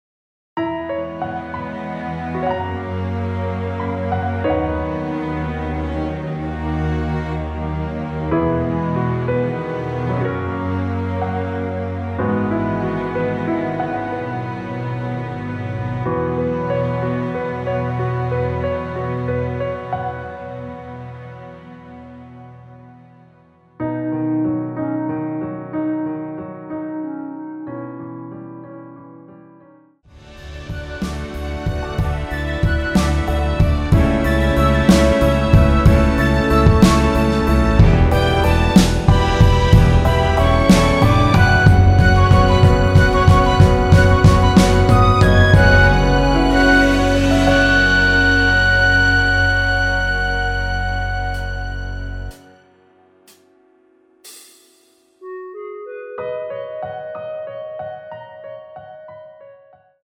3분 14초경 무반주 후 노래 들어가는 부분 박자 맞추기 쉽게 카운트 추가하여 놓았습니다.(미리듣기 확인)
원키에서(-2)내린 멜로디 포함된 MR입니다.
앞부분30초, 뒷부분30초씩 편집해서 올려 드리고 있습니다.